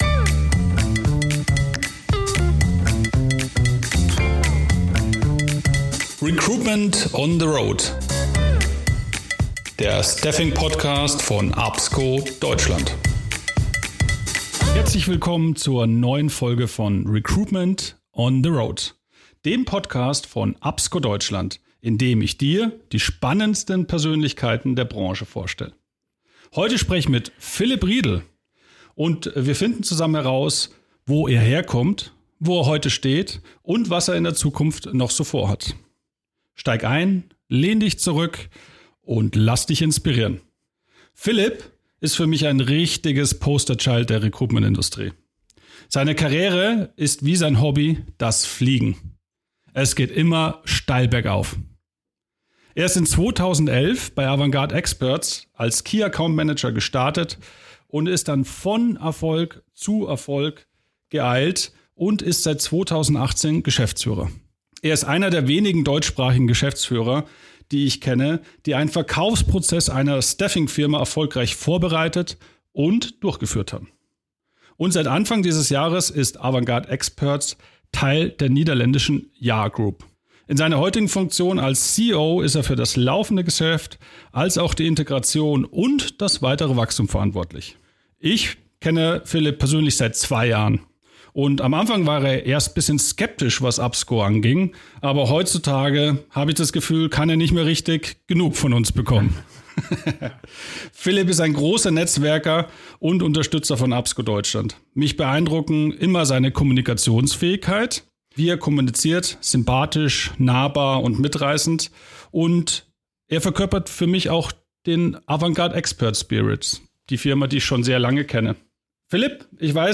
Freut Euch auf ein sehr persönliches Gespräch, auf positive Visionen und jede Menge Mut!